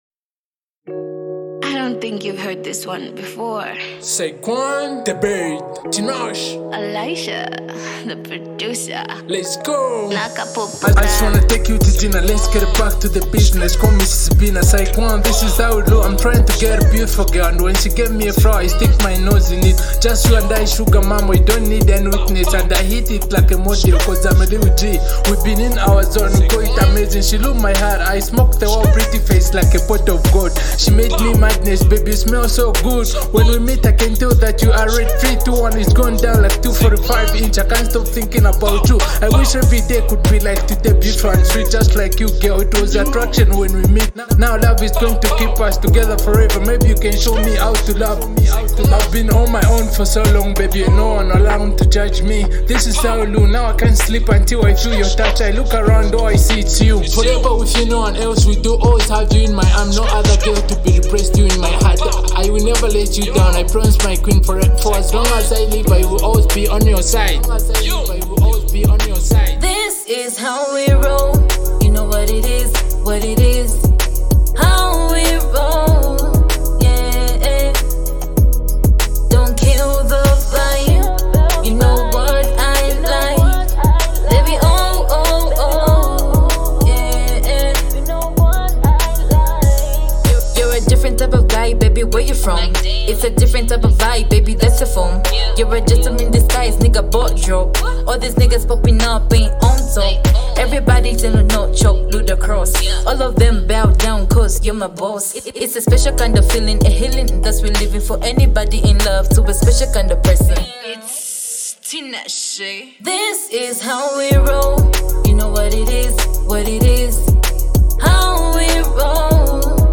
Love song hip hop